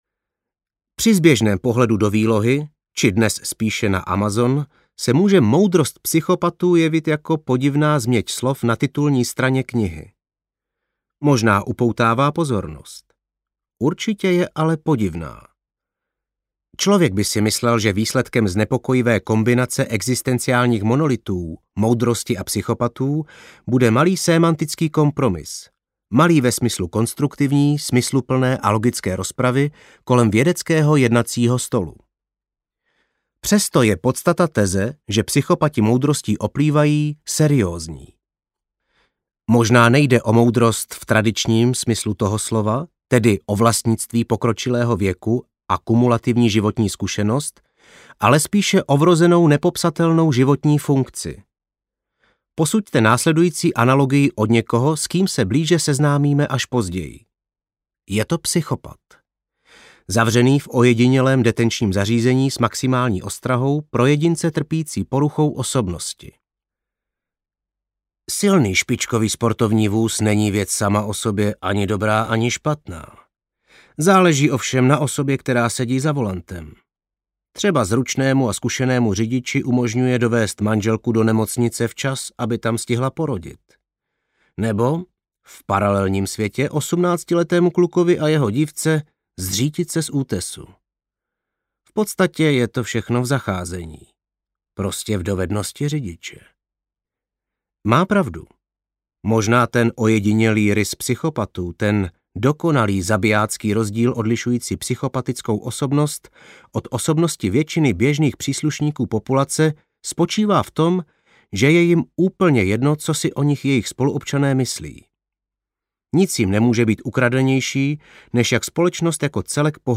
Moudrost psychopatů audiokniha
Ukázka z knihy